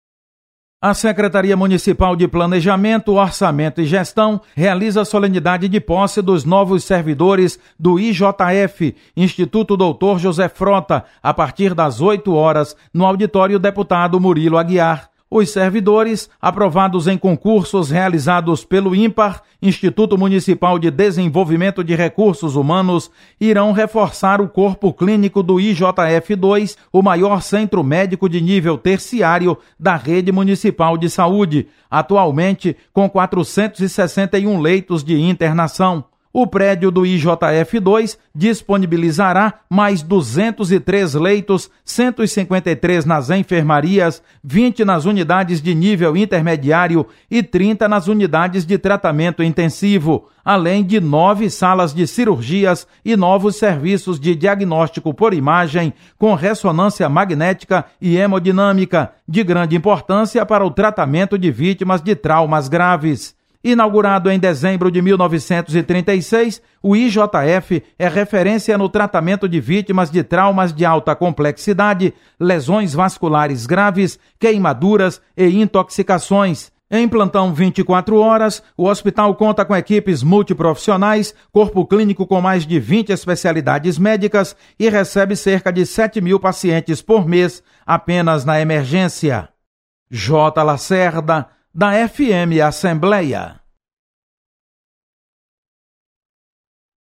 Acompanhe as atividades desta sexta-feira na Assembleia Legislativa. Repórter